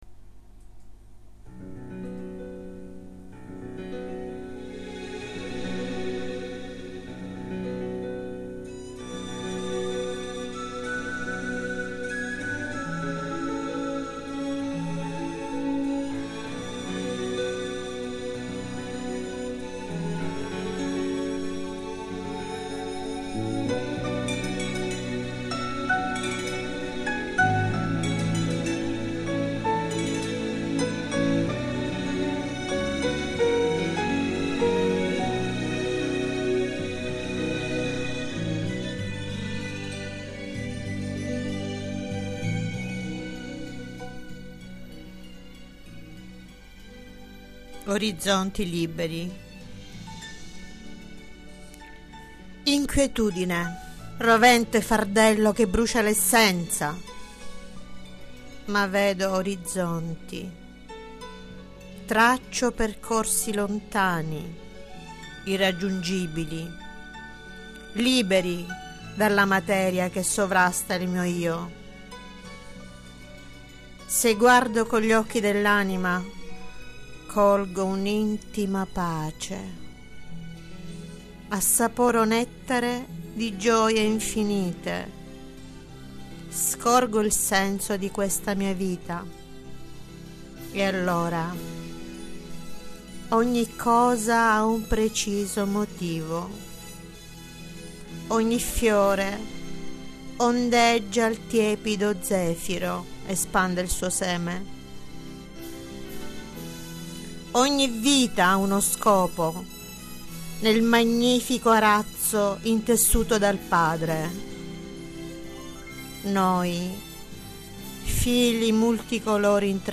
POESIE RECITATE - I SENTIERI DEL CUORE - Gabitos